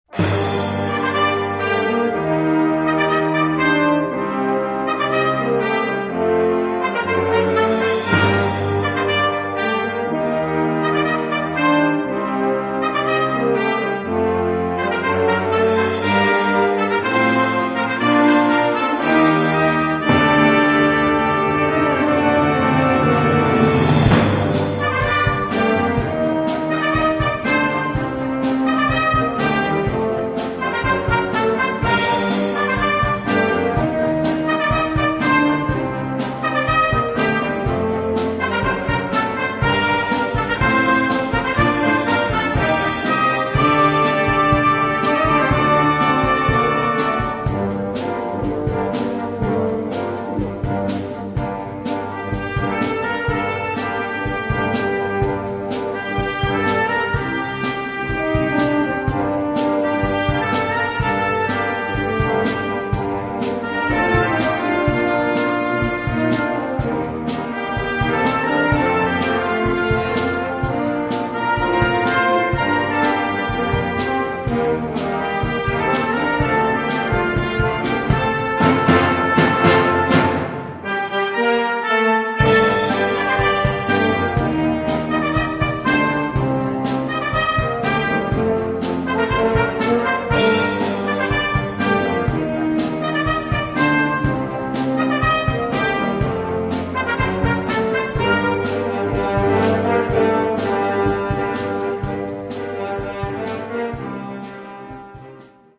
Gattung: Moderne Blasmusik
Besetzung: Blasorchester